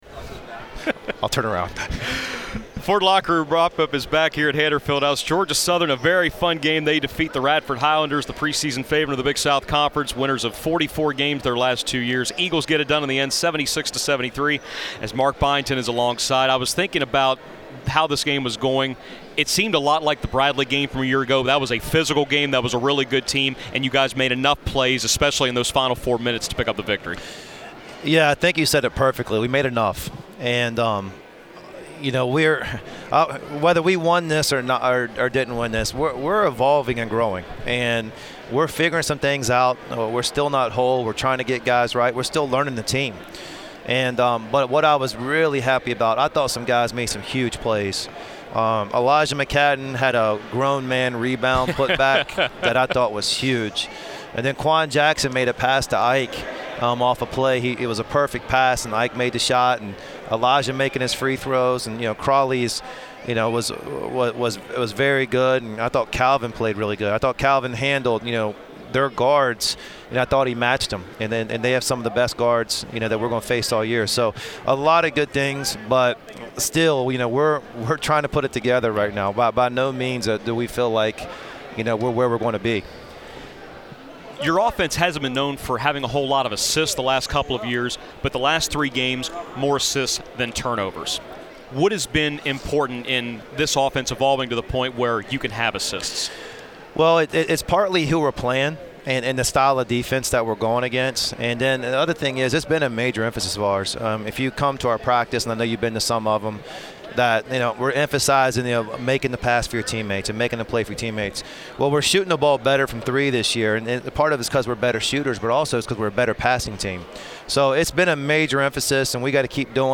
Postgame radio interview